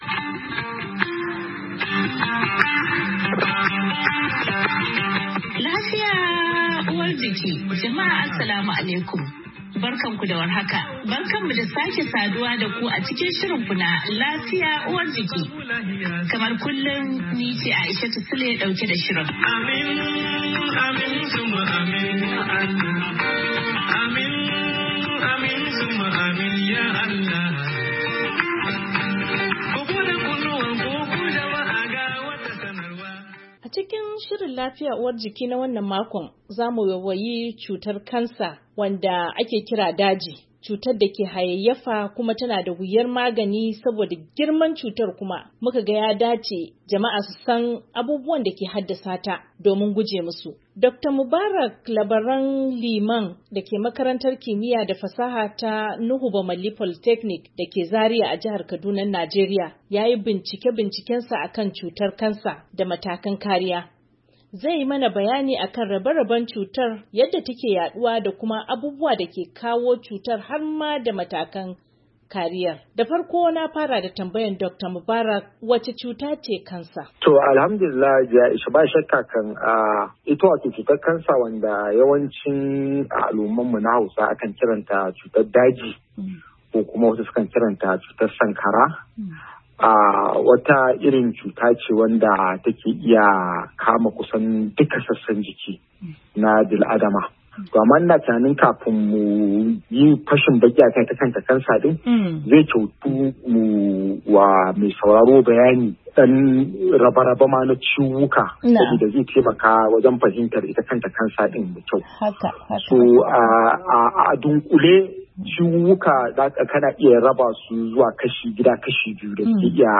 LAFIYA UWAR JIKI: Tattaunawa